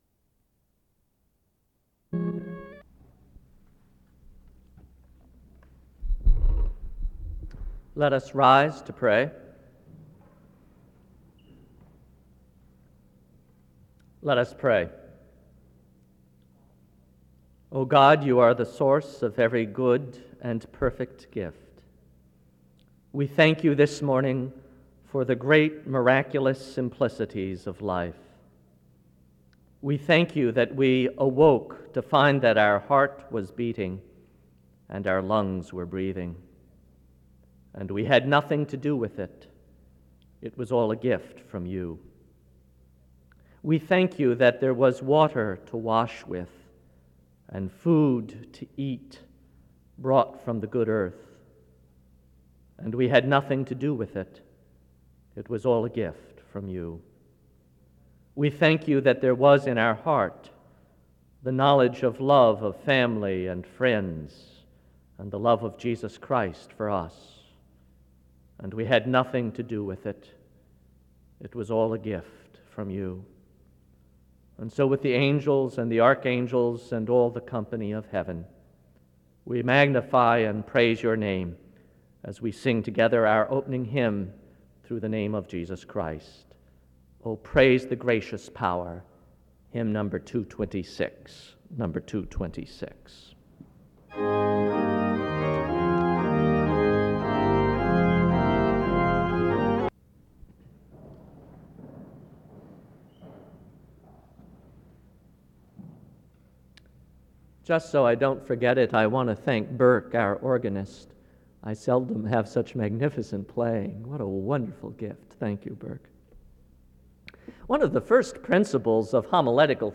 Download .mp3 Description The service starts with prayer from 0:00-1:26.
Wake Forest (N.C.)